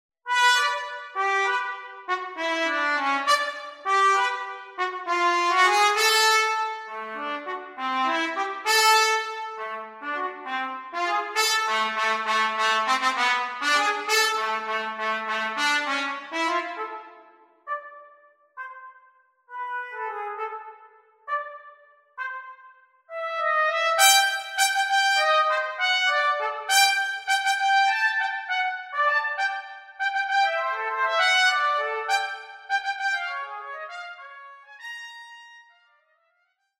unaccompanied trumpet